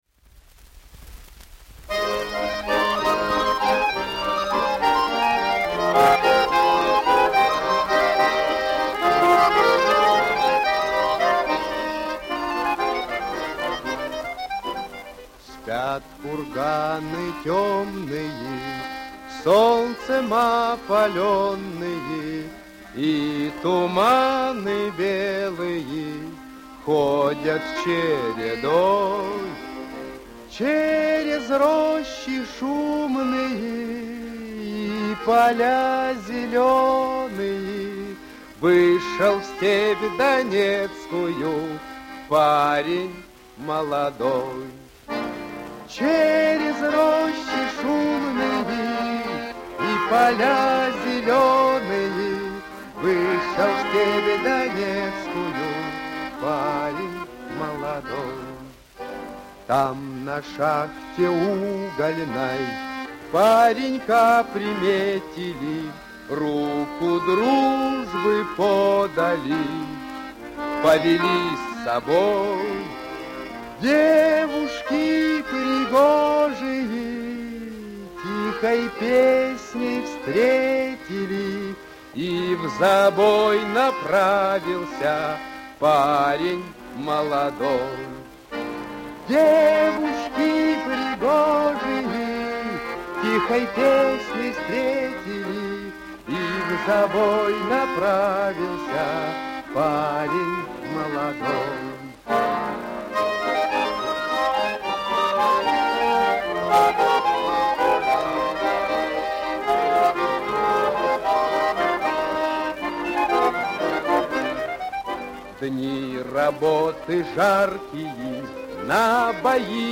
Саундтрек